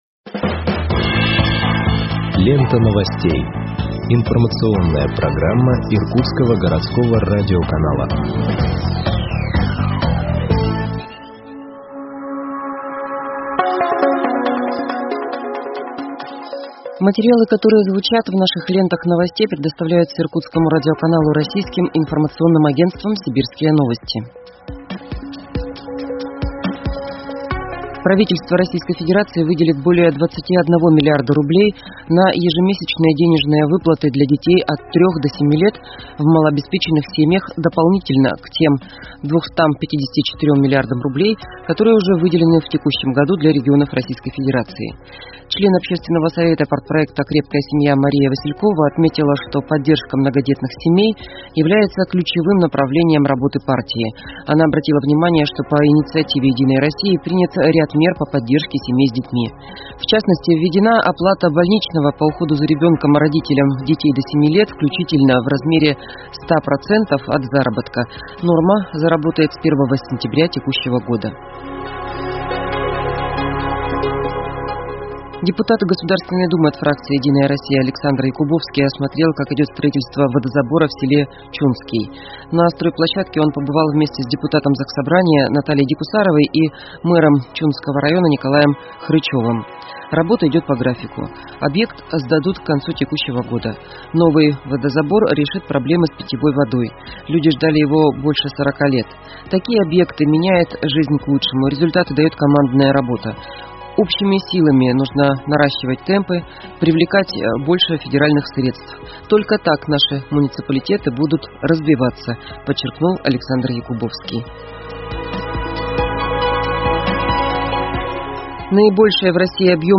Выпуск новостей в подкастах газеты Иркутск от 19.08.2021 № 2